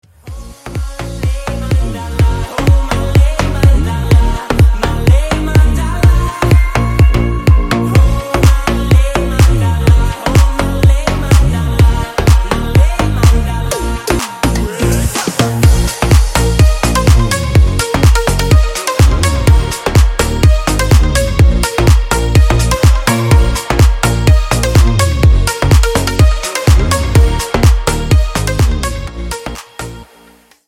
• Качество: 128, Stereo
громкие
remix
зажигательные
Club House
басы